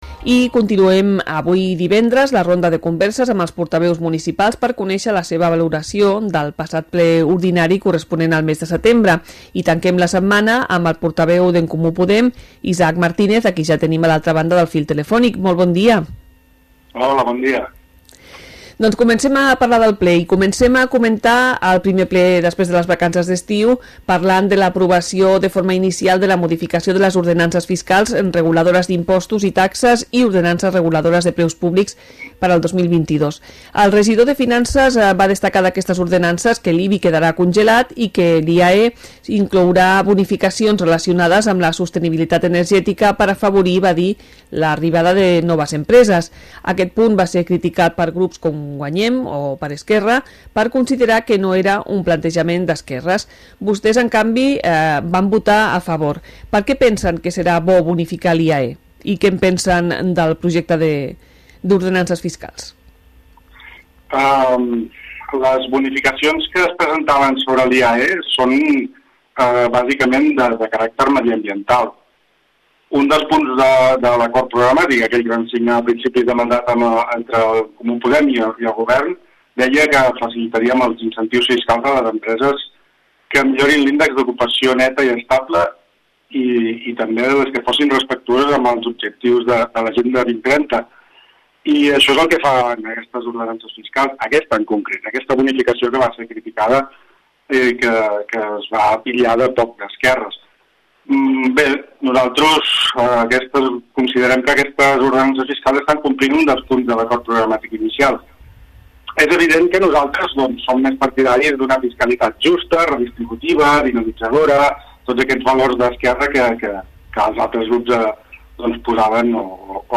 Entrevista-Isaac-Martínez-ECP-Ple-setembre.mp3